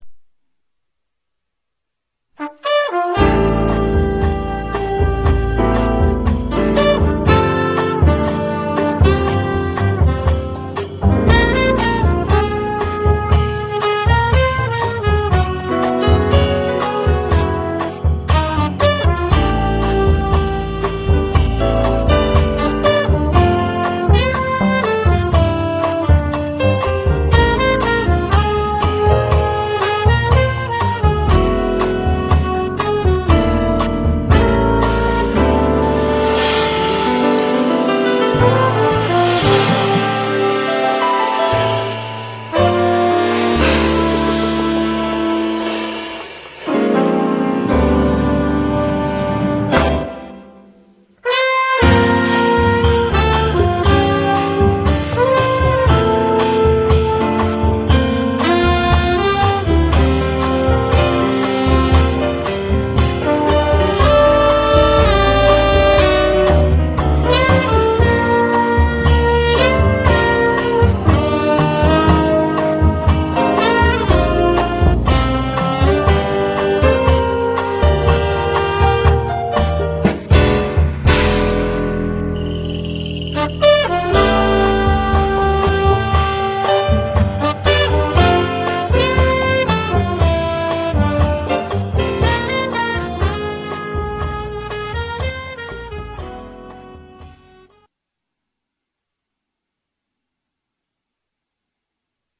Drums
Trumpet
Tenor Saxophone
Piano
Bass
Percussion